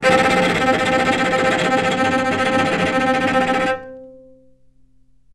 vc_trm-C4-mf.aif